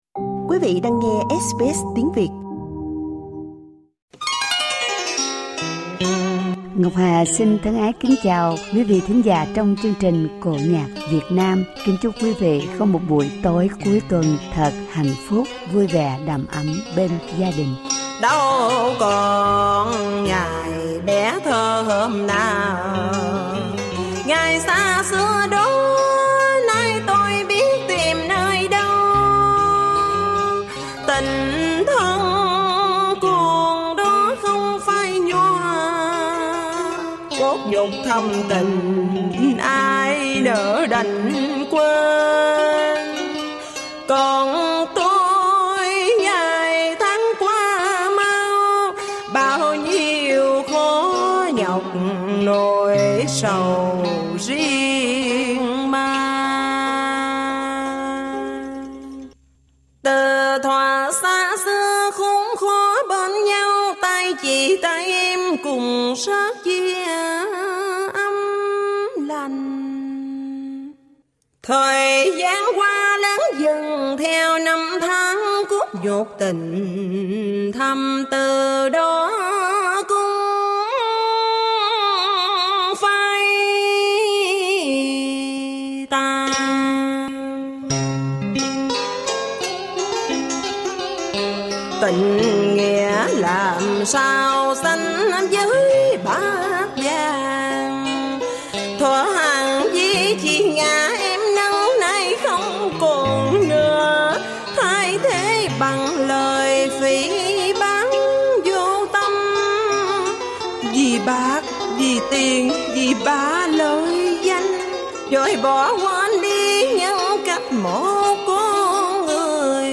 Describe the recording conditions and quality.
Trong dịp lễ giỗ tổ tại Brisbane